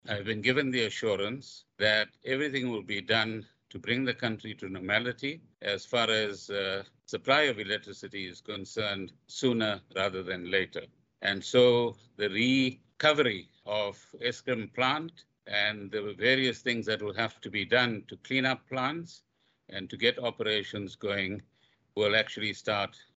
Gordhan yesterday, during a press briefing, announced that a wage agreement was reached yesterday between unions and Eskom.